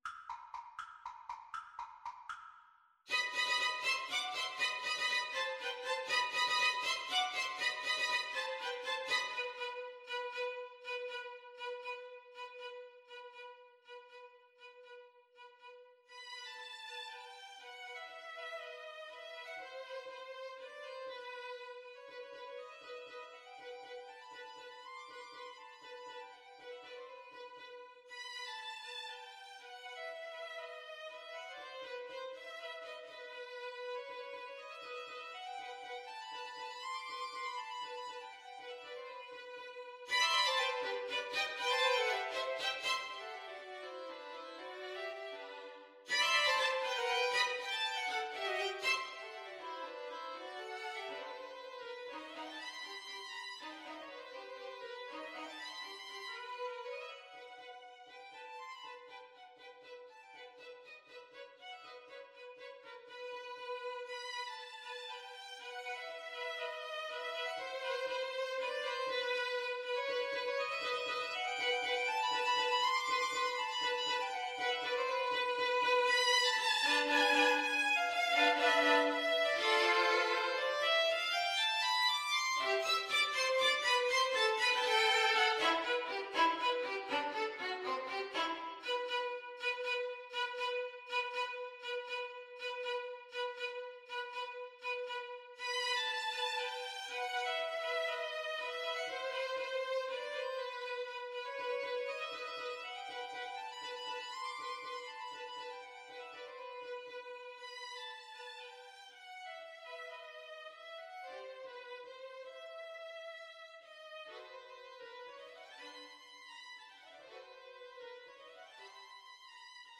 Allegro vivo (.=80) (View more music marked Allegro)
Violin Trio  (View more Advanced Violin Trio Music)
Classical (View more Classical Violin Trio Music)